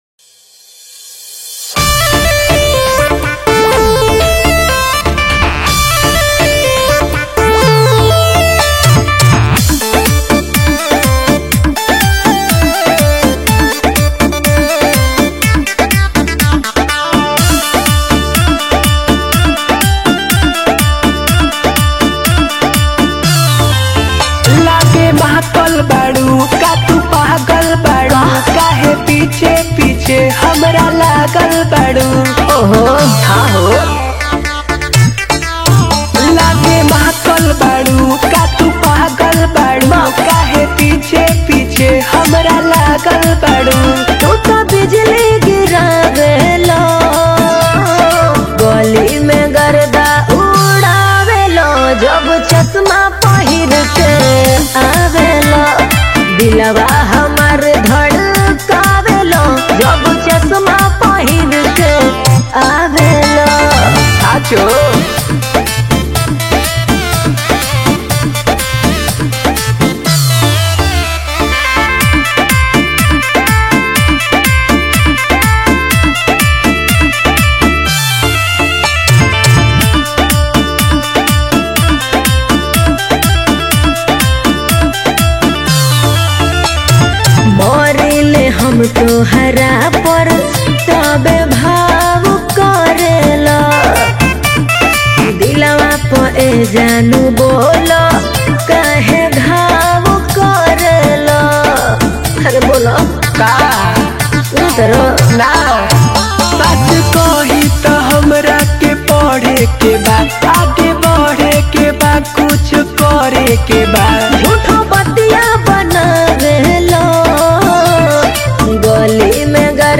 Bhojpuri Rangdari Song